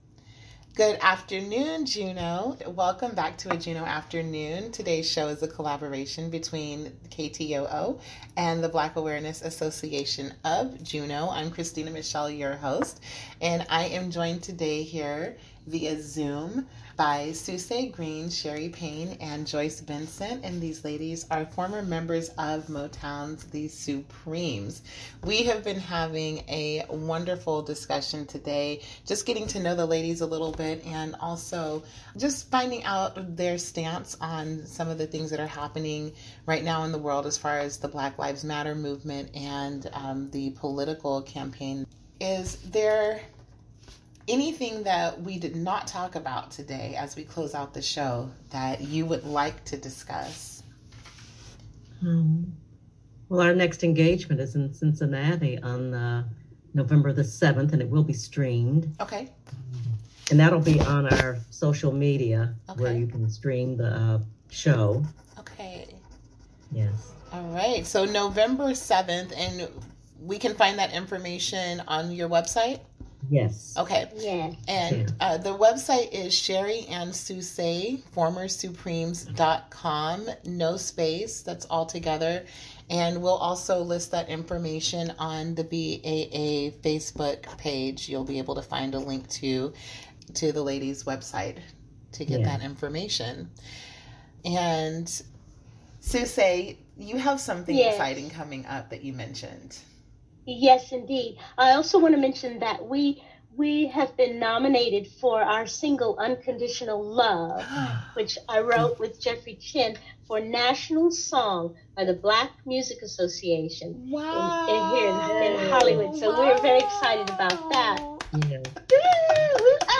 Hear their stories of fame, friendship, and more, along with some great music, as they talk with members of the Black Awareness Association of Juneau.